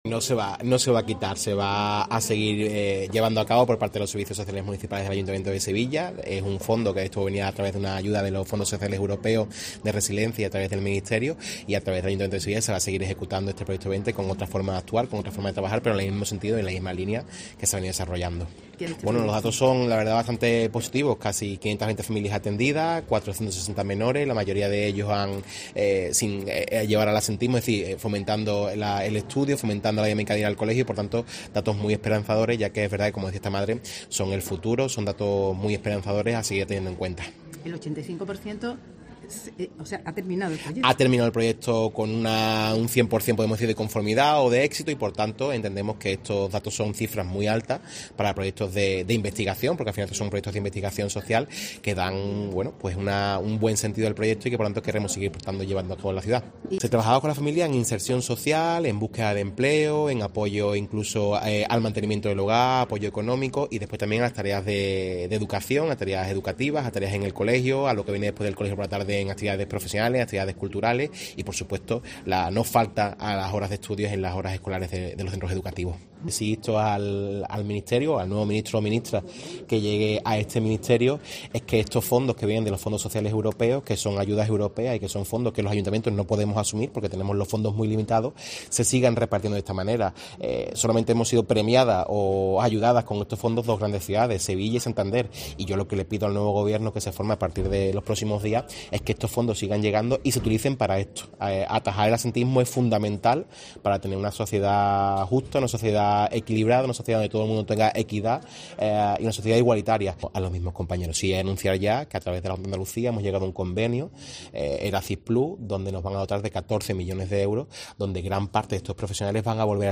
Acto de clausura del proyecto "Vente"